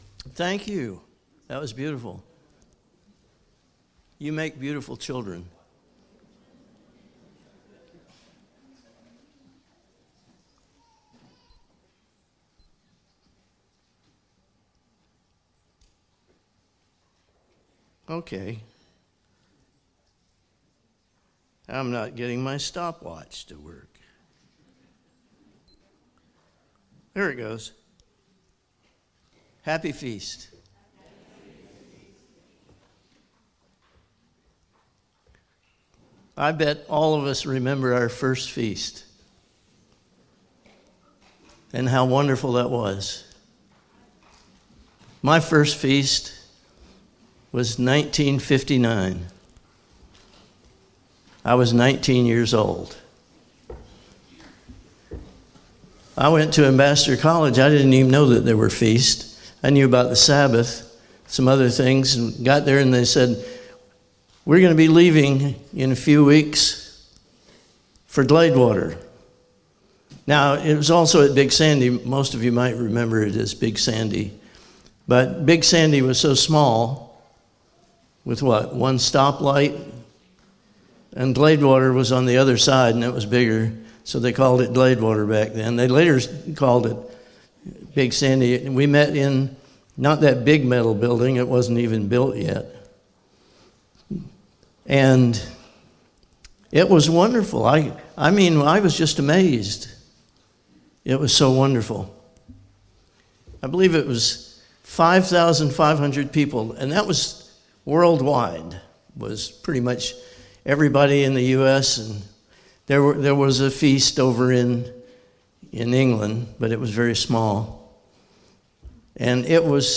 Sermons
Given in Walnut Creek, Ohio